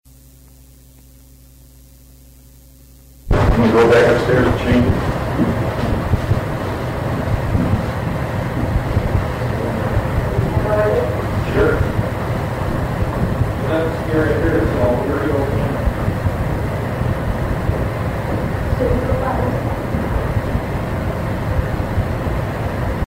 The other EVP was recorded in the old kitchen where the reported apparition of a young girl had been seen time and time again.
In the background on the cassette tape you will hear our voices become more distant as we walked towards the stairwell away from the recording unit. A very clear, direct, female response of “Say Goodbye!” was heard on the cassette tape. It’s the last voice you hear on the clip below.
A slight echo resonated during this response.